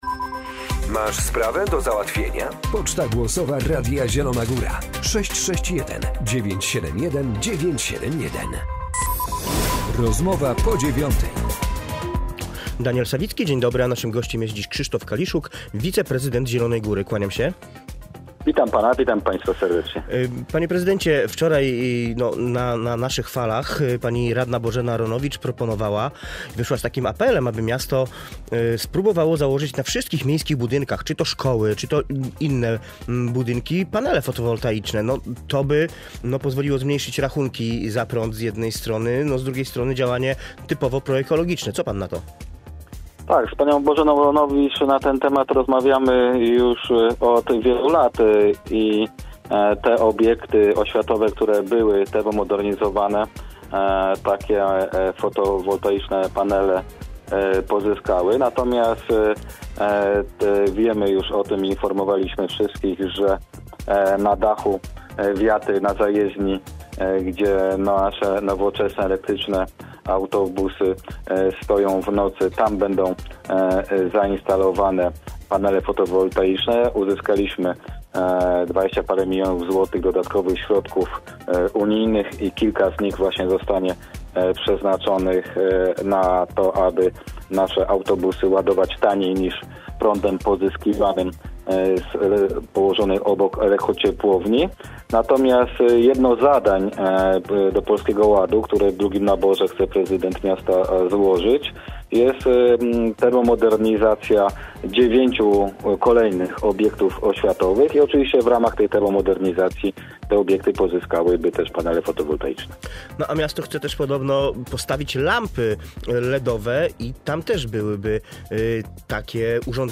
Krzysztof Kaliszuk, wiceprezydent Zielonej Góry